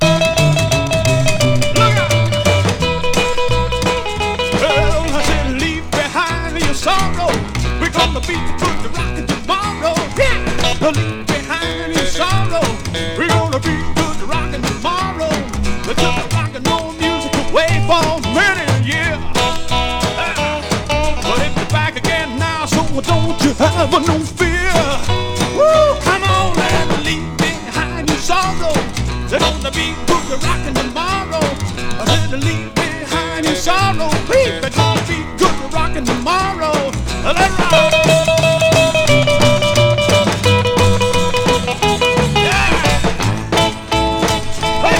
Rock, Rockabilly　USA　12inchレコード　33rpm　Mono